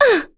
Yuria-Vox_Damage_01.wav